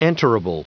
Prononciation du mot enterable en anglais (fichier audio)
Prononciation du mot : enterable